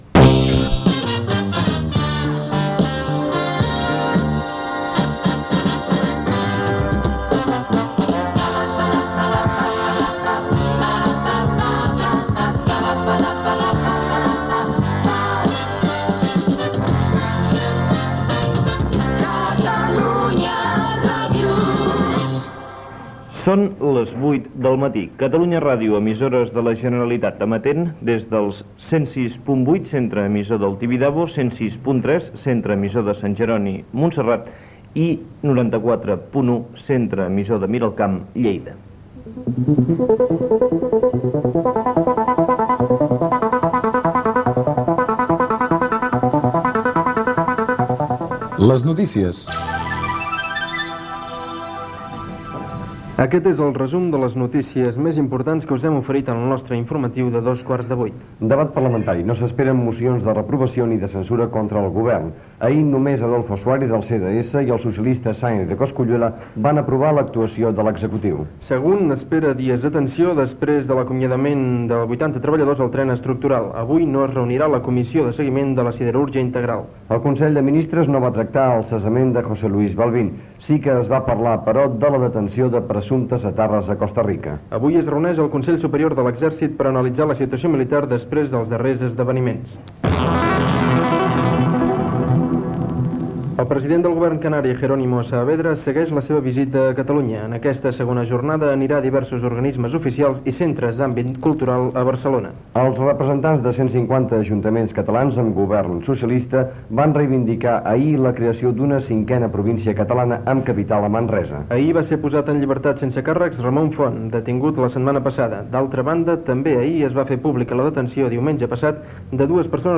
Indicatiu de l'emissora, hora (8 hores), freqüències d'emissió, indicatiu (veu Miquel Calçada), resum informatiu
Informatiu
FM